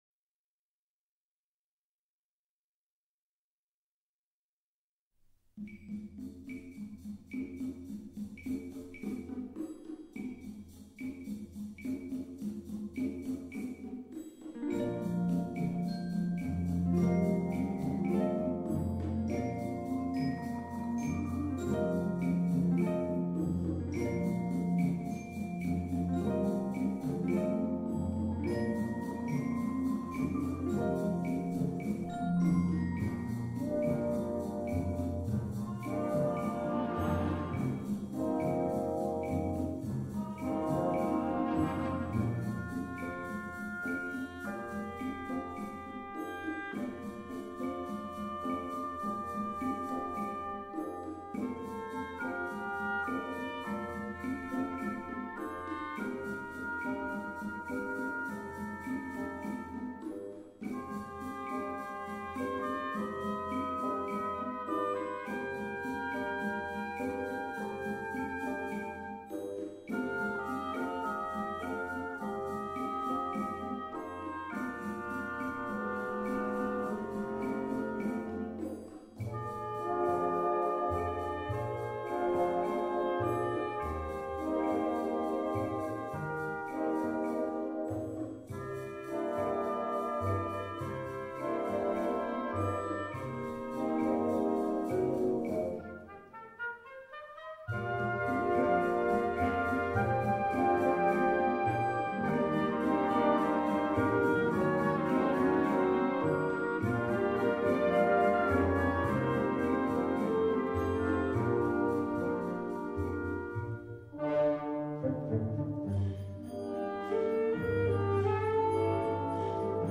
Genre: Band
Timpani (Guiro)
Percussion 1: Maracas, Triangle (1)
Percussion 2: Congas, Bongos (1)
Percussion 3: Claves, Floor Tom (1)
Marimba (1)
Vibraphone (1)